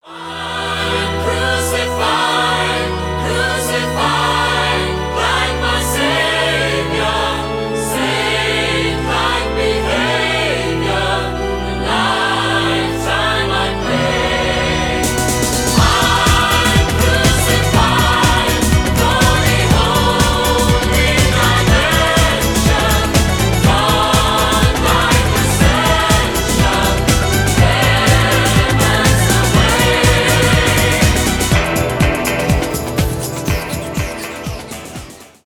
евродэнс
dance pop